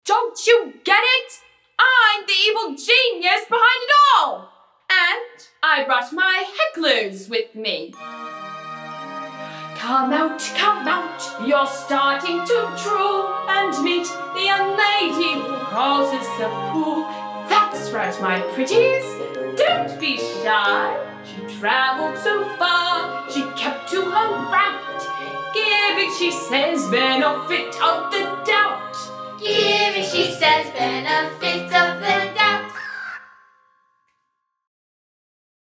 Forte Cast Recording
Forte Summer Theater presented the world premiere of Pluck! on July 26 & 27, 2019 in Apple Valley, Minnesota